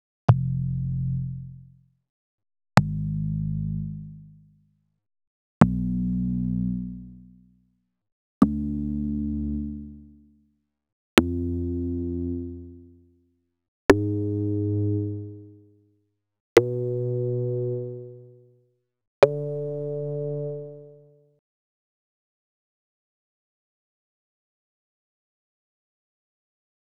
42_PPGPercBass_F+3_1-2.wav